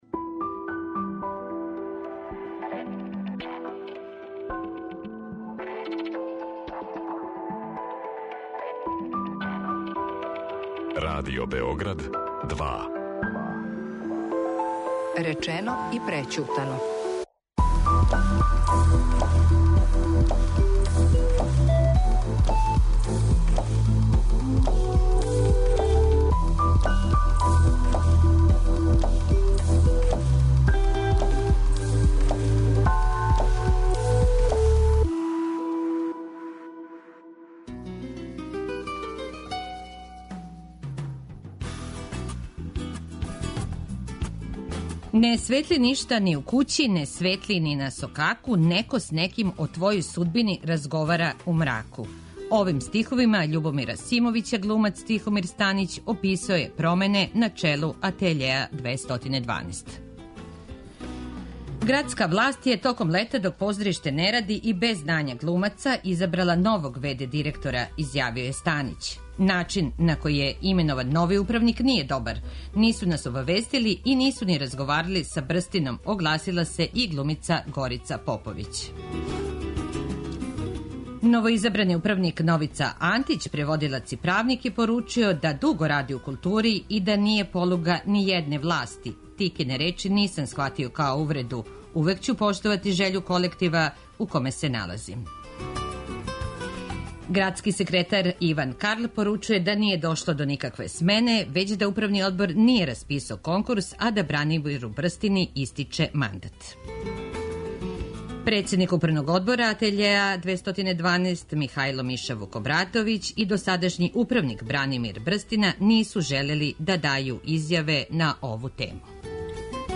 Гости: Тихомир Станић, глумац